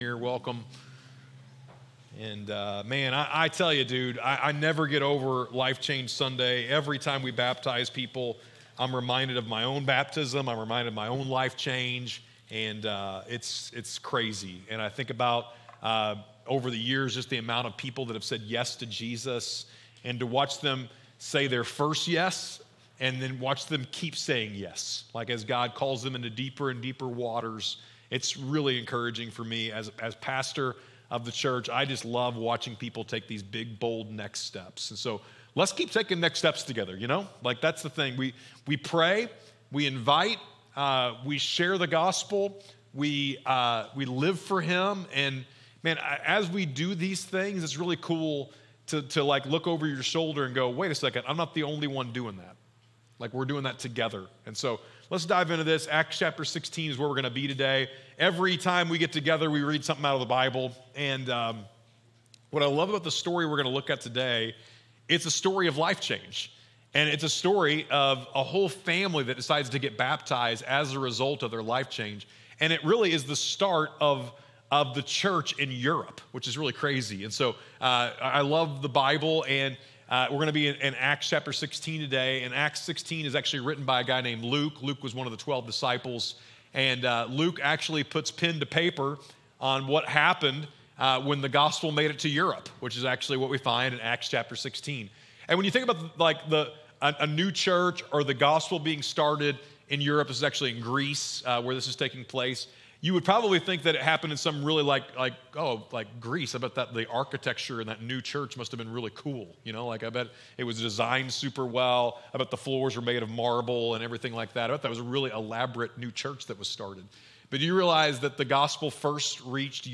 On Life Change Sunday, we’ll explore where faith really begins and celebrate baptisms as we see how one encounter with Jesus transformed a man, his home, and his future. If you’re still figuring out faith, ready to take a step, or just need hope that real change is possible, this is a sermon you won’t want to miss.